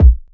aint got time kick.wav